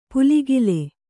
♪ puligil